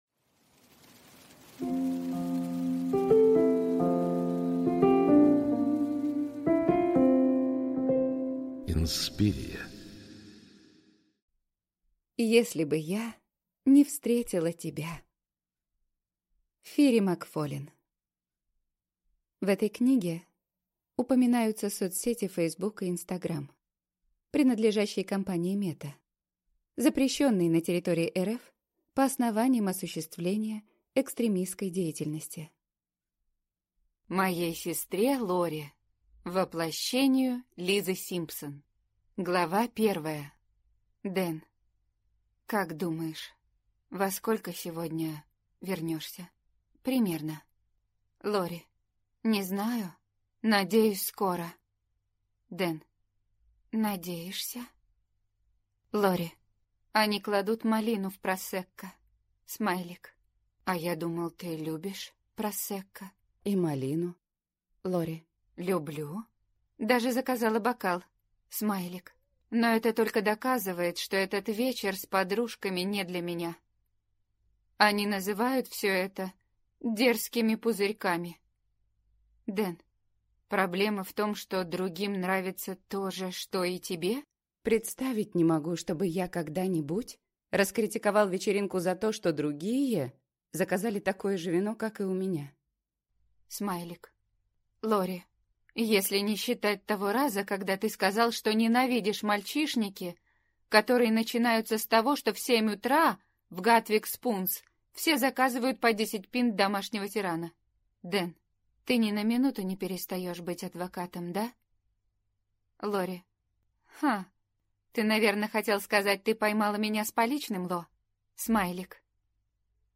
Аудиокнига Если бы я не встретила тебя | Библиотека аудиокниг
Прослушать и бесплатно скачать фрагмент аудиокниги